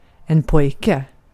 Ääntäminen
US : IPA : [bɔɪ]